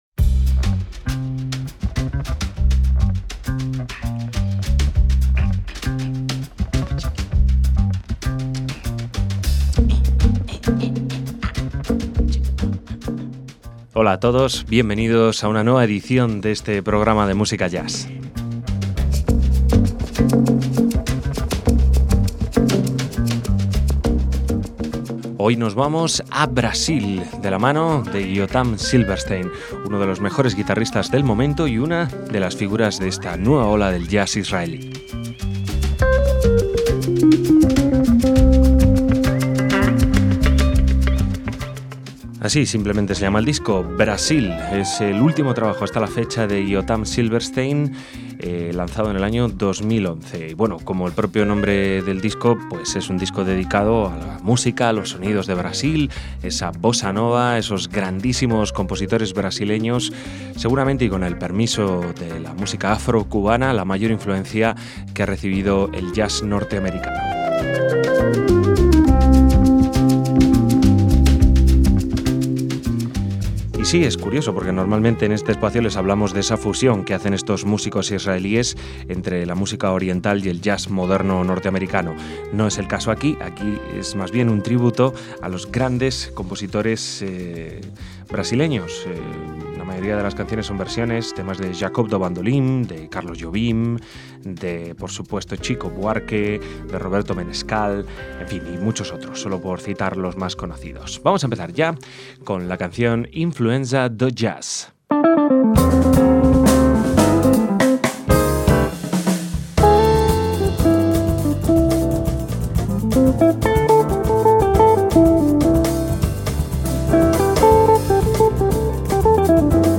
bossa nova
piano
contrabajo
batería y percusión
clarinete